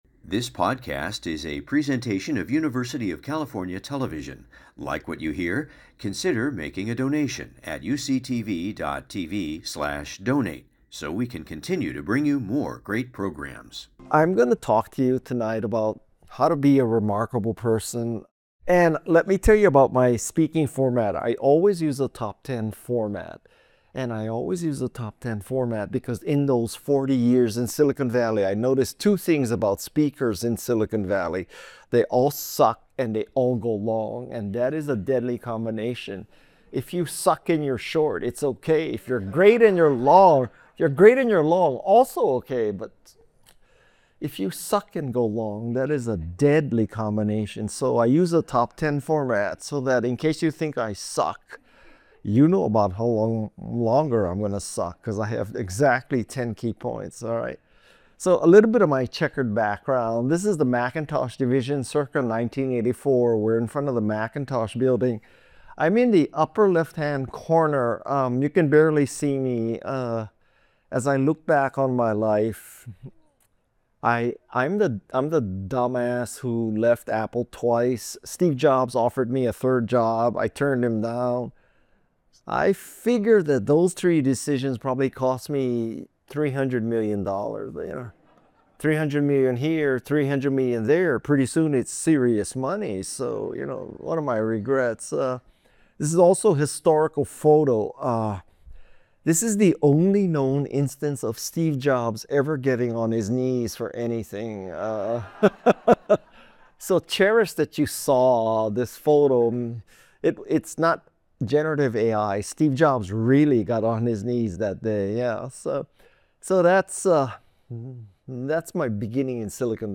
How do we motivate people to make changes that impact the greater good? The field of sustainability and behavior change is working to do just that. Hear from experts about job growth in this field and how you can put your passion for conservation to work.